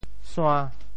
“腺”字用潮州话怎么说？
腺 部首拼音 部首 月 总笔划 13 部外笔划 9 普通话 xiàn 潮州发音 潮州 suan5 白 suan3 白 中文解释 腺 <名> 生物体内能分泌某些化学物质的组织 [gland]。